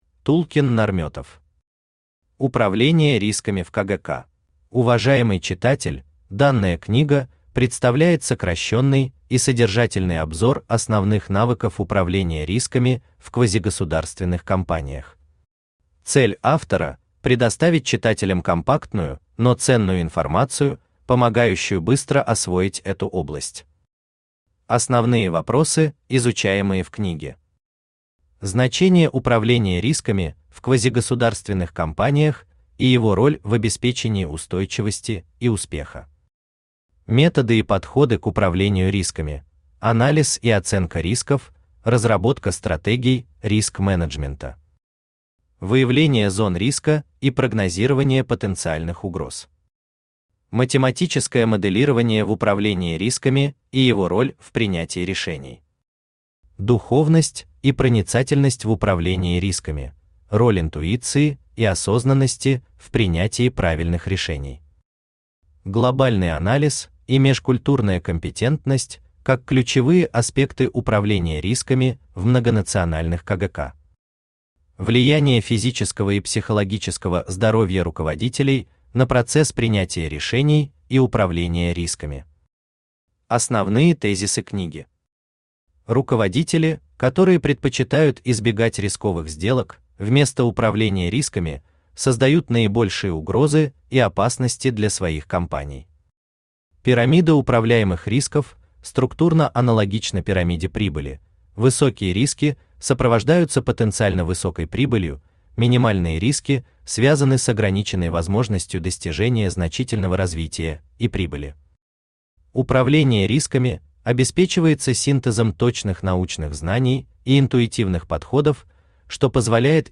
Аудиокнига Управление рисками | Библиотека аудиокниг
Aудиокнига Управление рисками Автор Тулкин Нарметов Читает аудиокнигу Авточтец ЛитРес.